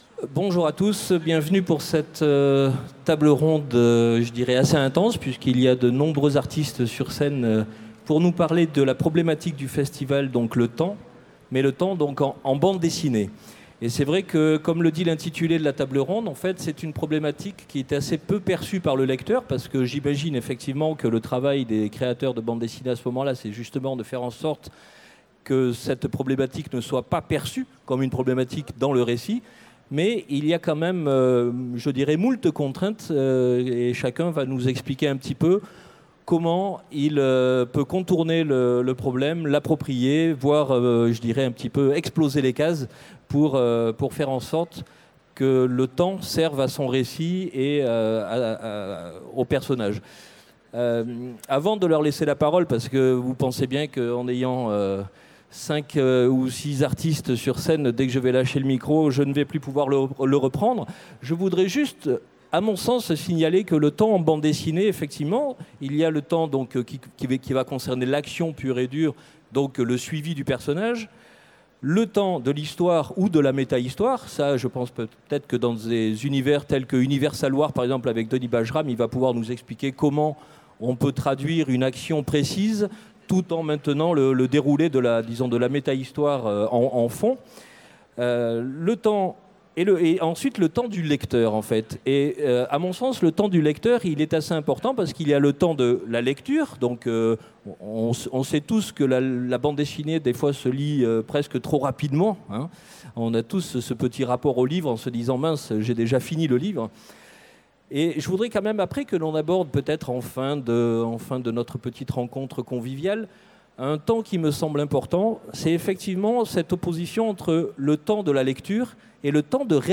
Utopiales 2017 : Conférence Le temps en BD
Conférence